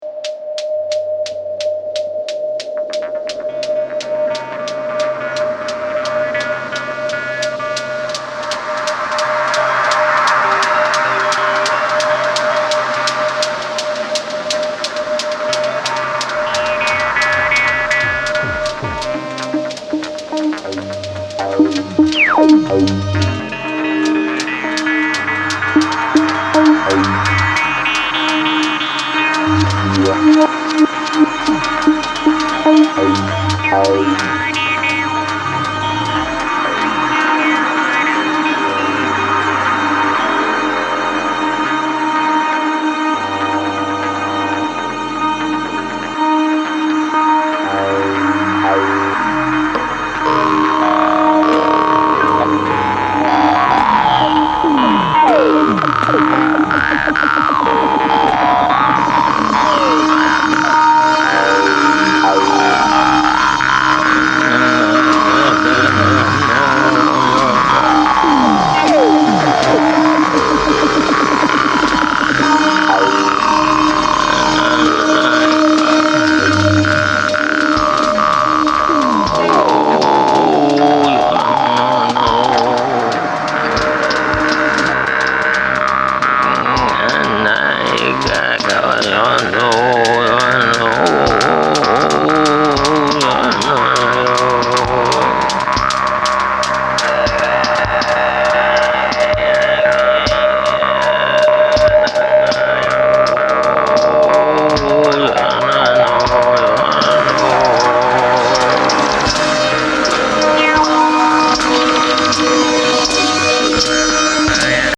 Field recording, music, and sound design